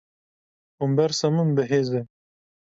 Pronounced as (IPA)
/heːz/